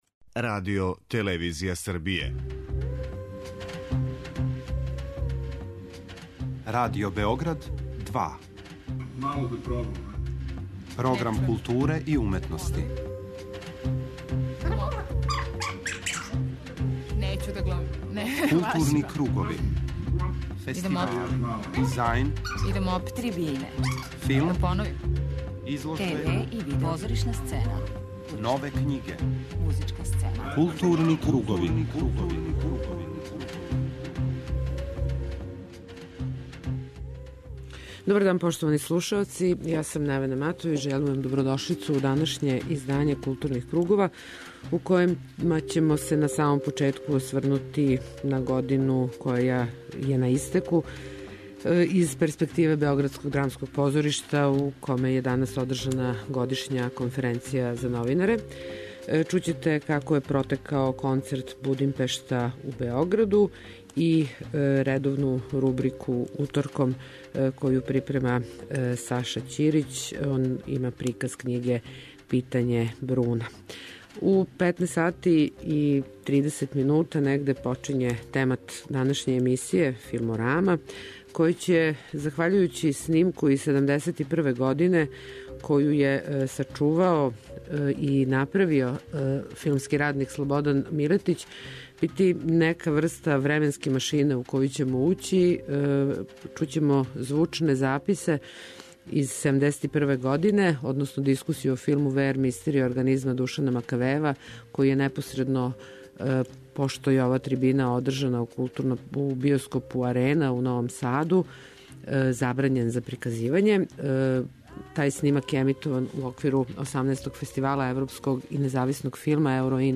У првом делу емисије бележимо најзначајније догађаје из културе, а у 'Филморами' ћете слушати ексклузивнe делове звучног записа из 1971. године - дискусију о филму 'WR-мистерије организма' Душана Макавејева, који је убрзо потом забрањен за јавно приказивање.